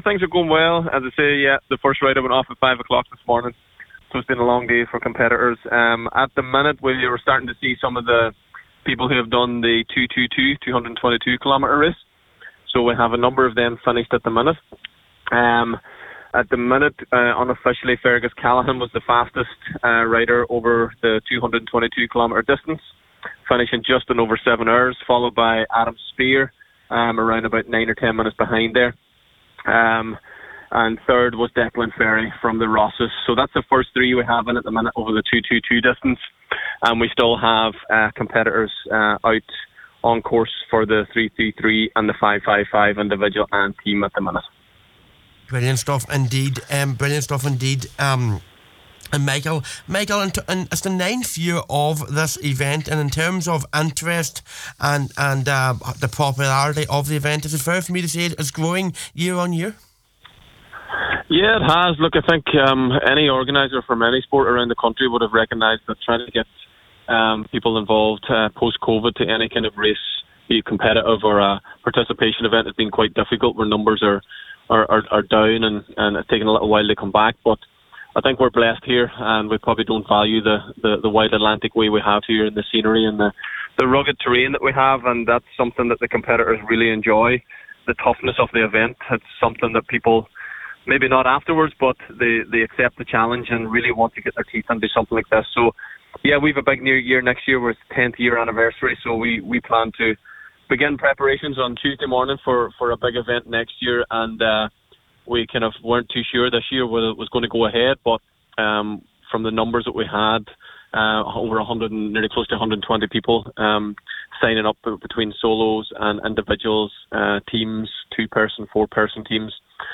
joined Highland Saturday Sport this afternoon at 3:30PM to give us the latest…